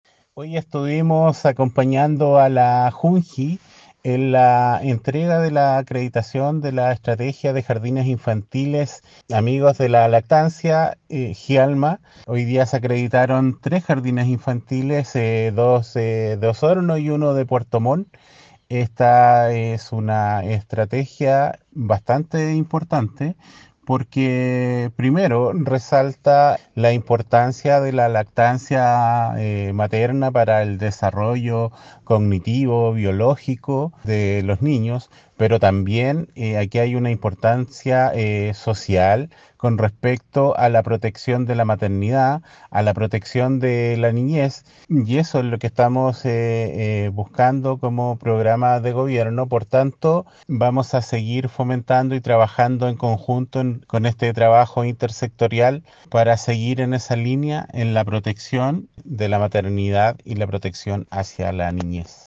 En este sentido, Claudio Millaguin, jefe provincial de la oficina de la Seremi de Salud de Osorno, resaltó el trabajo intersectorial desarrollado en beneficio a la protección de la maternidad y niñez.